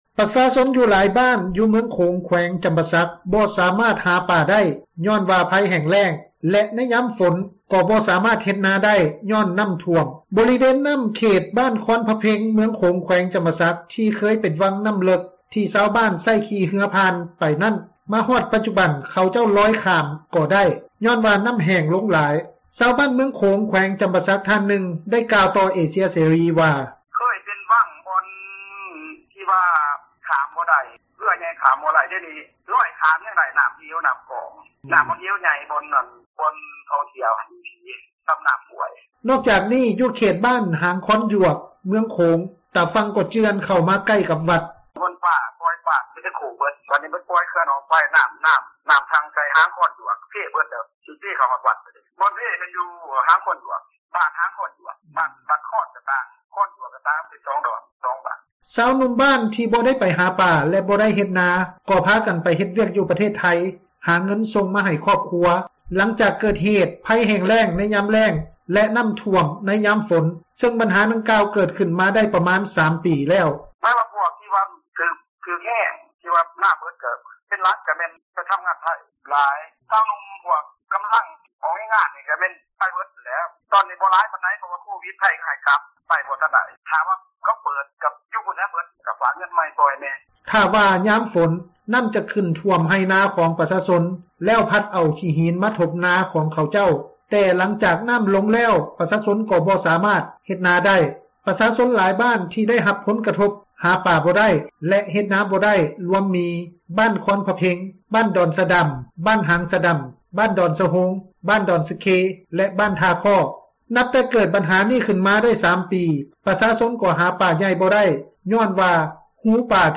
ຊາວບ້ານເມືອງຂົງ ທ່ານນຶ່ງ ໄດ້ກ່າວຕໍ່ເອເຊັຍເສຣີ ວ່າ:
ແມ່ເຖົ້າທ່ານນຶ່ງ ໄດ້ກ່າວຕໍ່ເອເຊັຽເສຣີ ວ່າ: